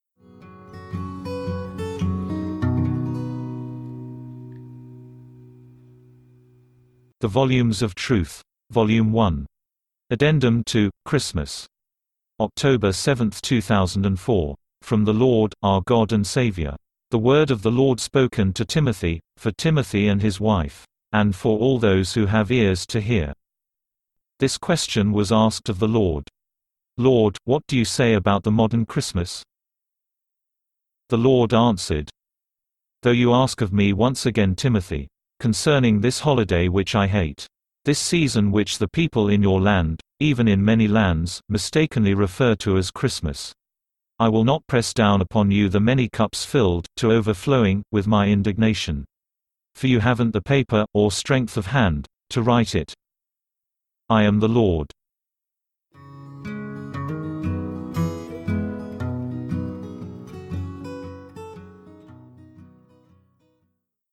File:V1.003.5 Addendum to Christmas (read by text-to-speech).mp3 - The Volumes of Truth
V1.003.5_Addendum_to_Christmas_(read_by_text-to-speech).mp3